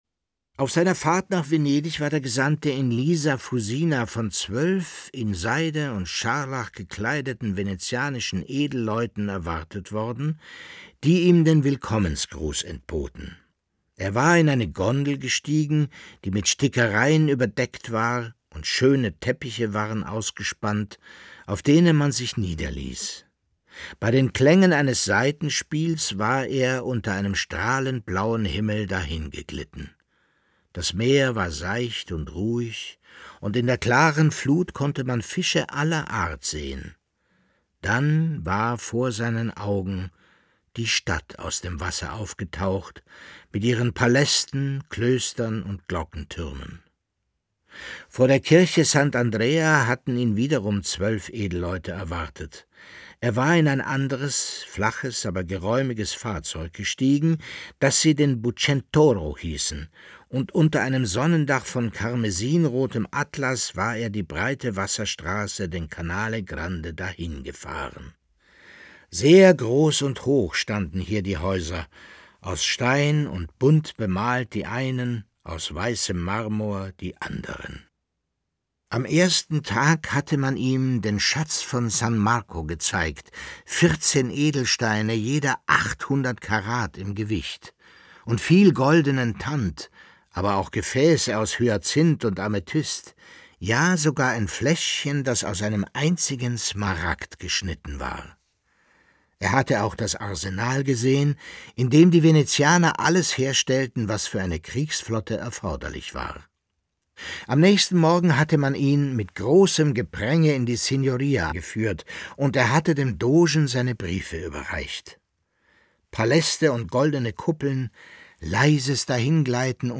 Leo Perutz: Nachts unter der steinernen Brücke (7/25) ~ Lesungen Podcast